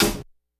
Perc_125.wav